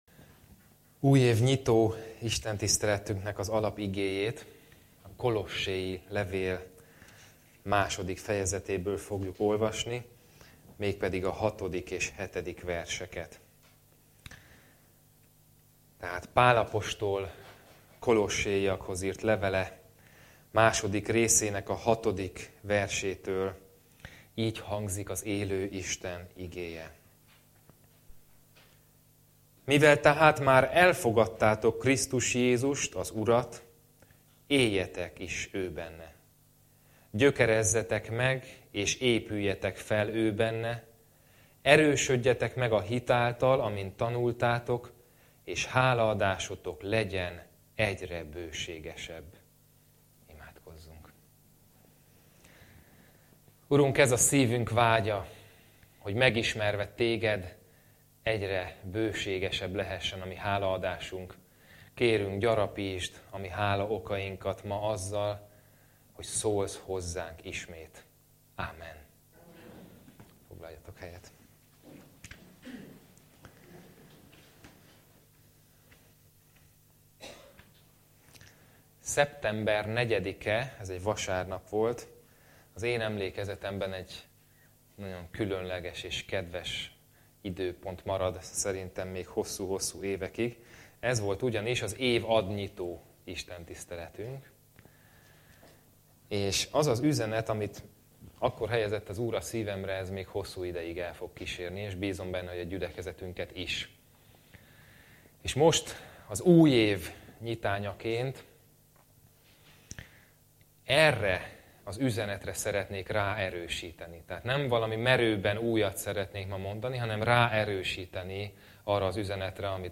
Passage: Kolossé 2,6-7 Service Type: Igehirdetés Bible Text